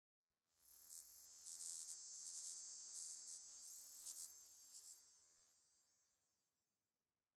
firefly_bush2.ogg